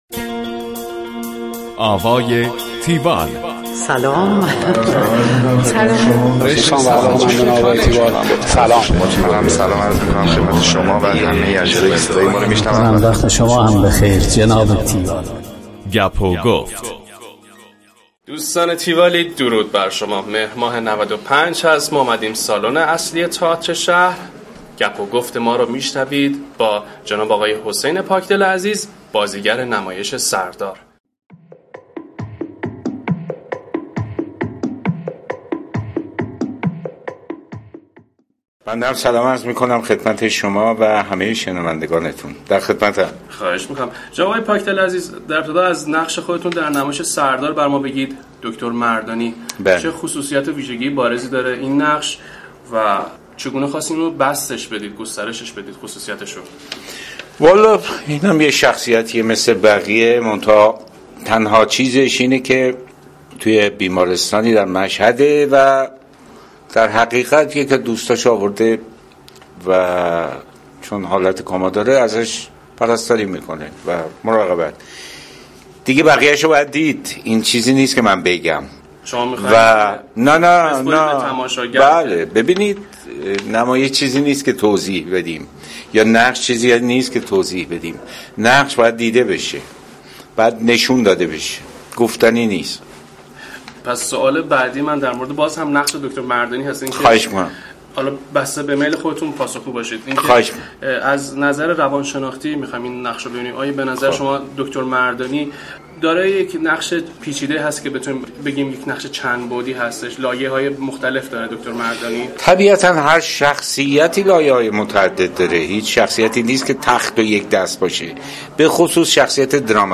گفتگوی تیوال با حسین پاکدل